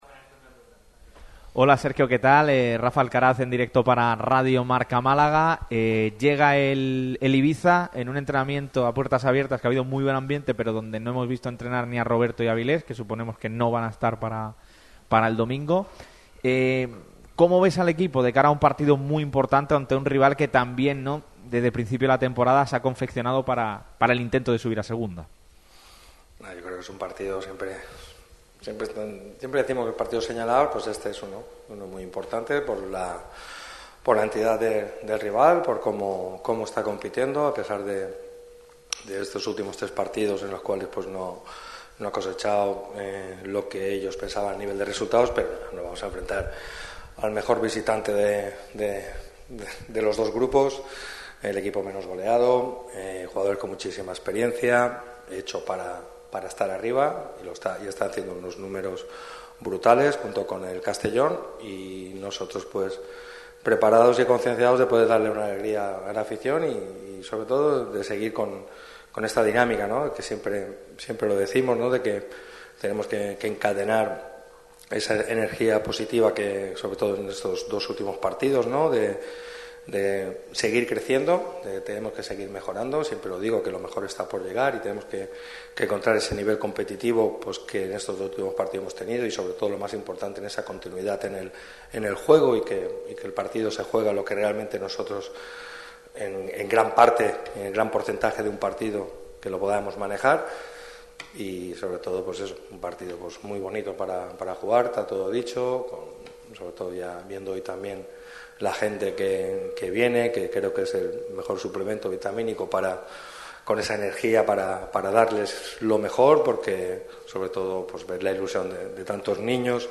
El entrenador del Málaga CF ha comparecido en la sala de prensa ‘Juan Cortés’ del estadio de La Rosaleda. El de Nules ha atendido a los medios en la rueda de prensa previa al compromiso clave contra la UD Ibiza el próximo domingo a las 12:00 horas.